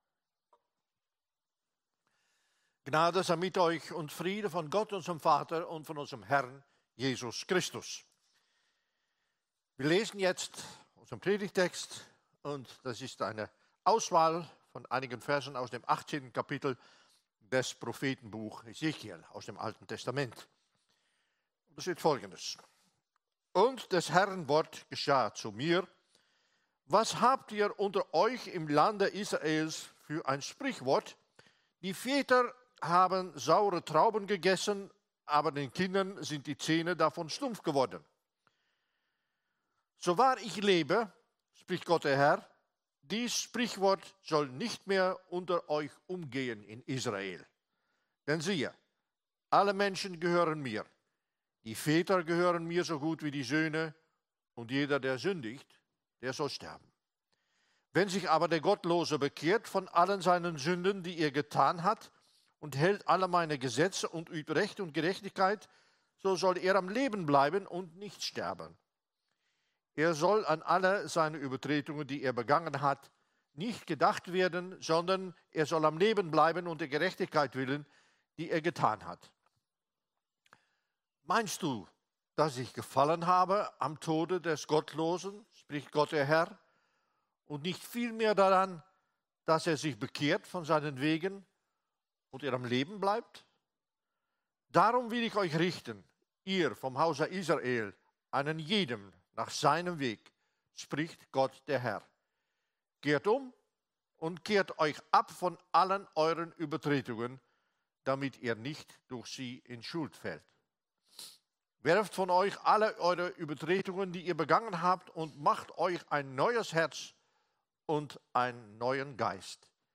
Predigten der Evangelischen Kirchengemeinde Durmersheim